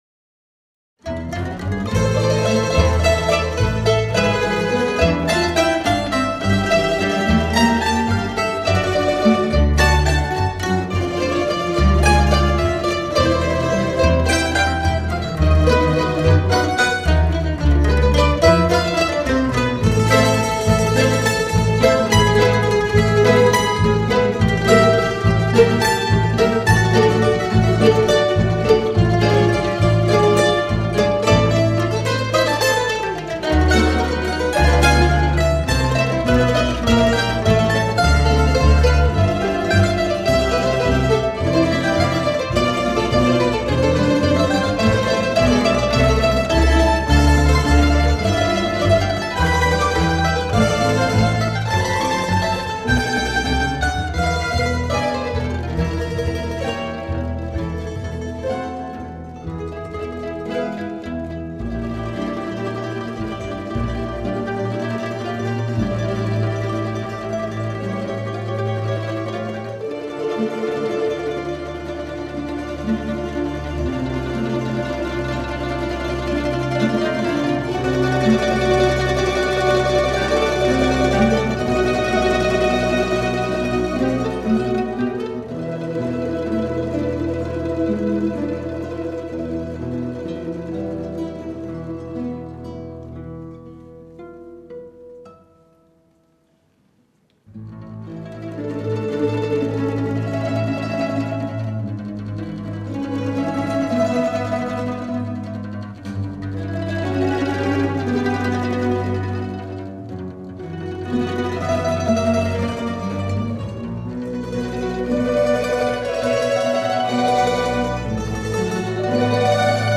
ORQUESTRA DE BANDOLINS
Os instrumentos que integram esta Orquestra são: bandolins, bandoletas, bândolas, bandoloncelo, violas e contrabaixo. O seu repertório inclui músicas de vários géneros: clássico, tradicional e ligeiro.
bandolins.mp3